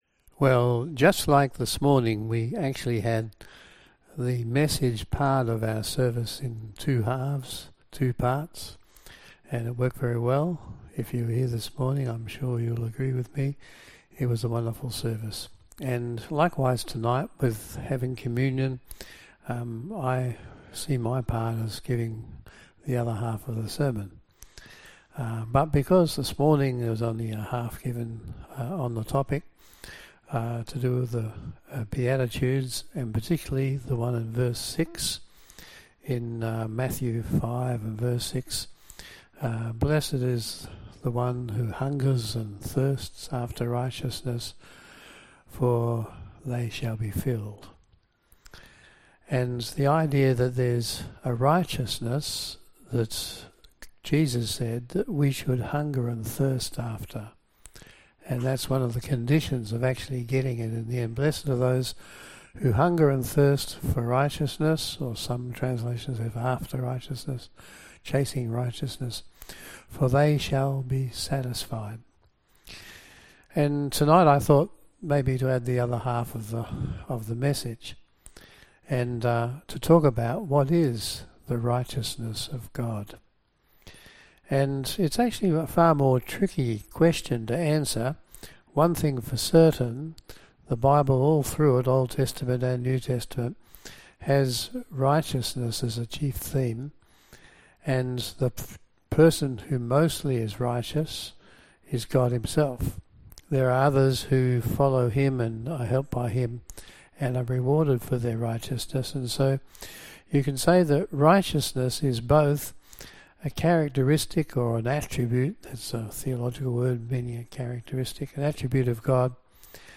Service Type: PM Service